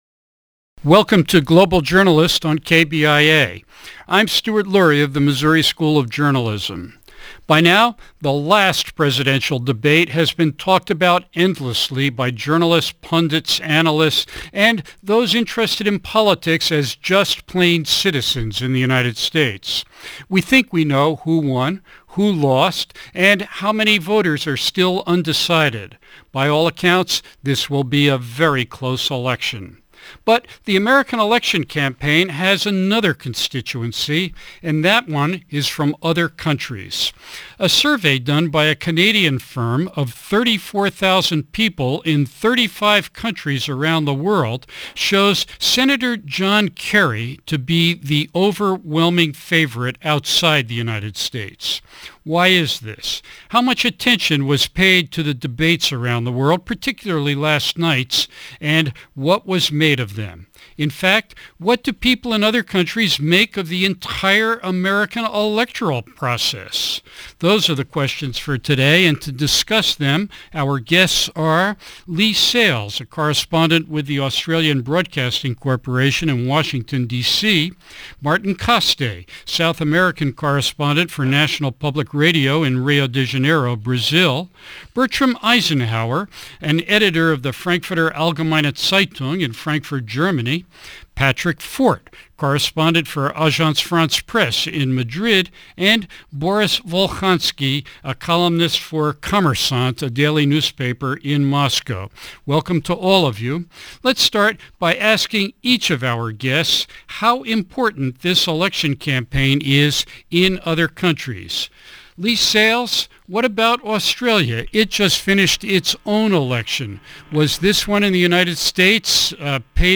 speaks with five journalists around the world to gain international perspectives on the 2004 Presidential Election.